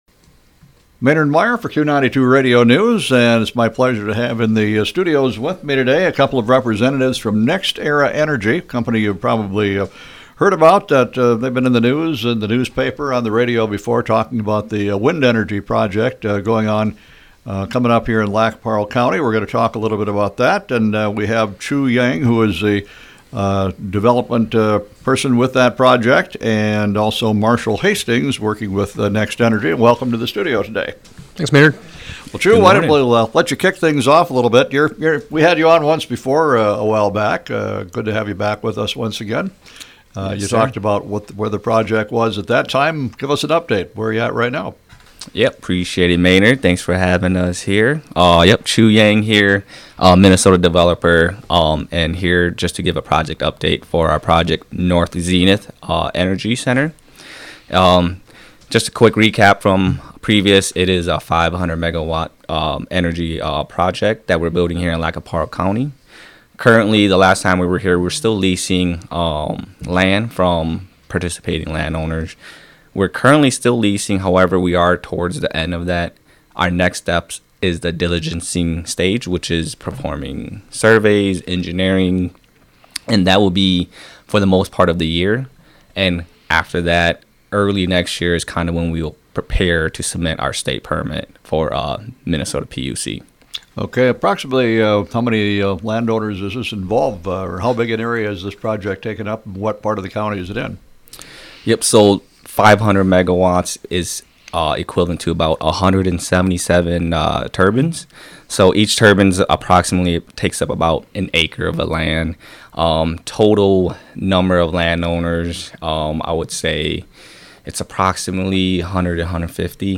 NEXTERA INTERVIEW
WINDTURBINE-INTERVIEW-NEXTERA.mp3